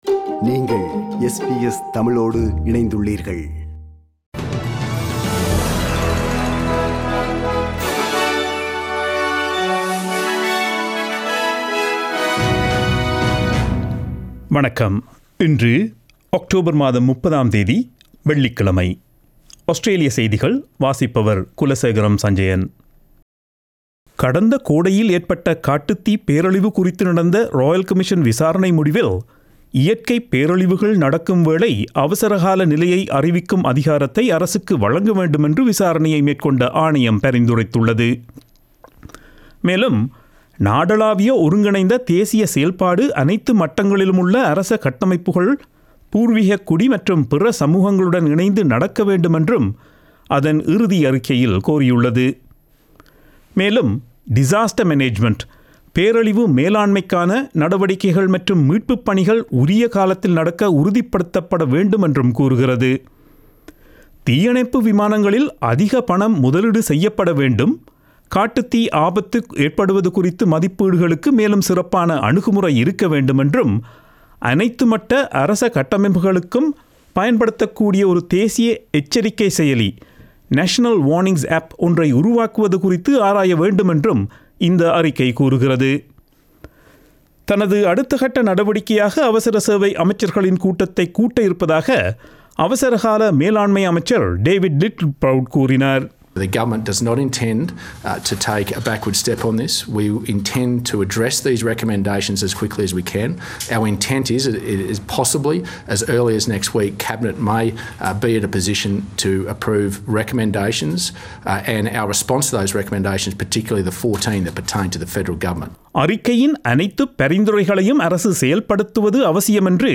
Australian news bulletin for Friday 30 October 2020.